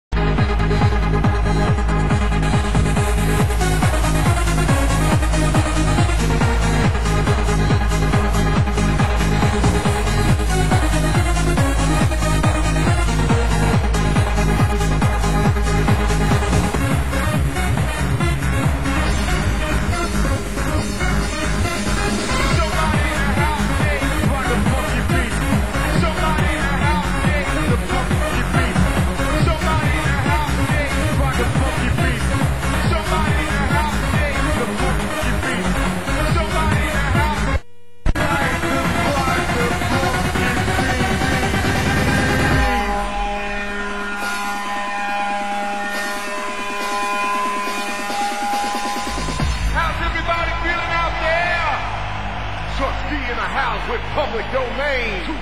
Genre Hard House